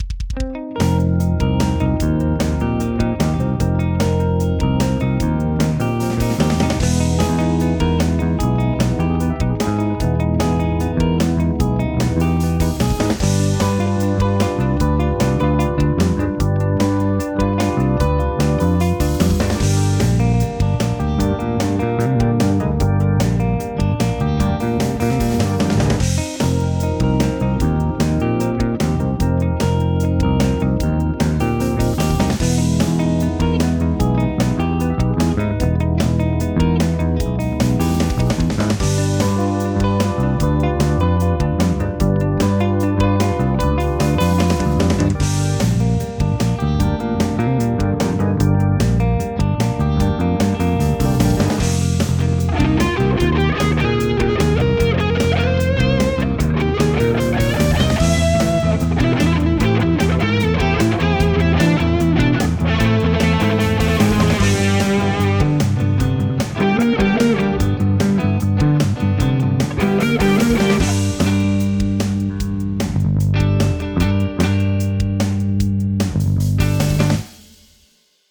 C quartel extended, Am, G major dominant 7, Gm.